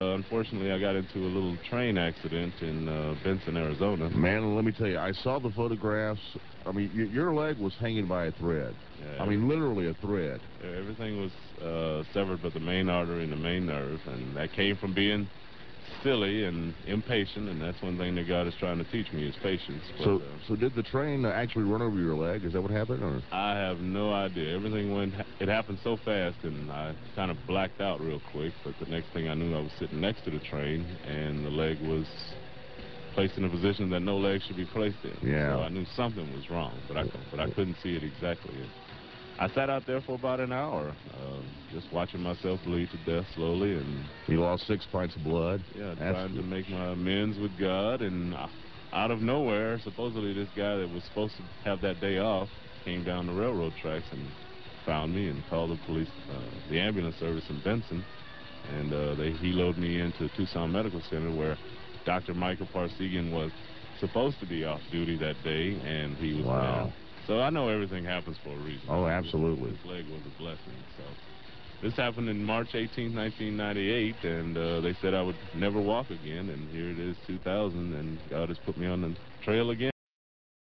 at KYJT radio Yuma, AZ